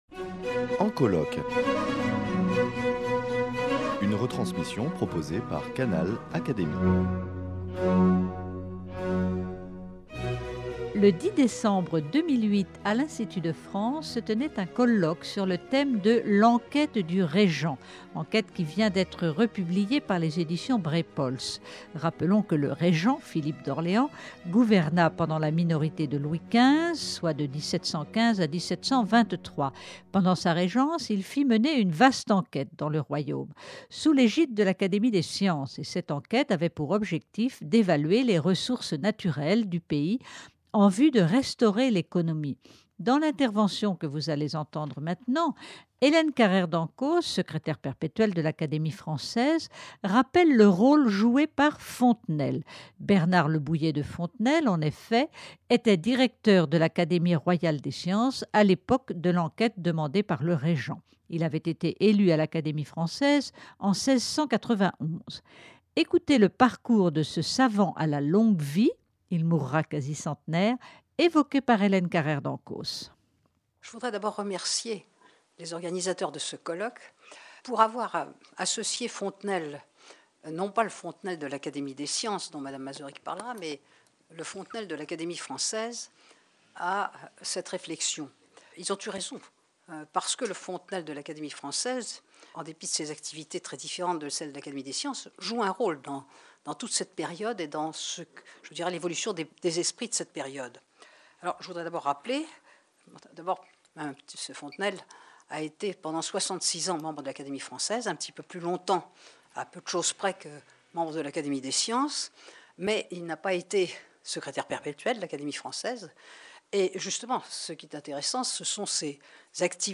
A l’occasion de la publication de cette enquête, une journée d’études inter-académique a été organisée à l’Institut, le 10 décembre 2008. Hélène Carrère d’Encausse, Secrétaire perpétuel de l’Académie française, a rappelé le rôle de Fontenelle (1657-1757), de l’Académie française, dans l’évolution des esprits durant le XVIIIème siècle.